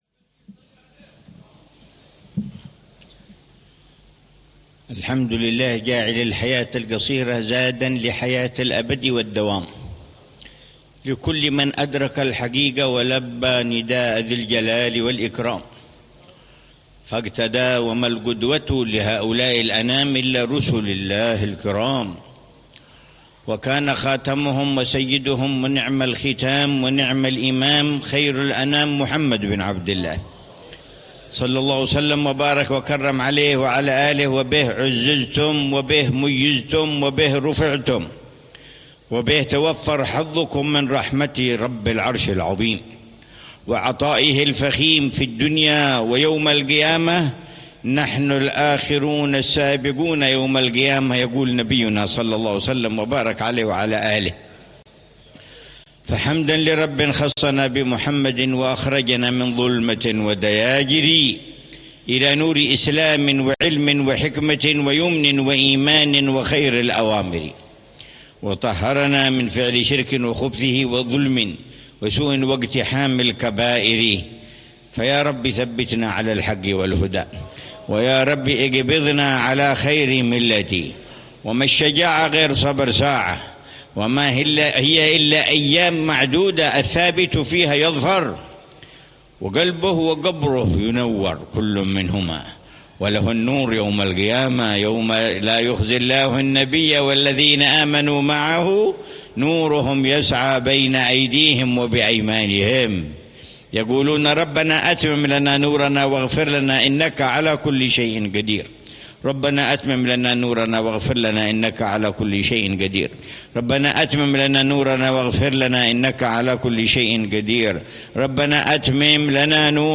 محاضرة العلامة الحبيب عمر بن محمد بن حفيظ ضمن سلسلة إرشادات السلوك في دار المصطفى، ليلة الجمعة 20 ربيع الأول 1447هـ، بعنوان: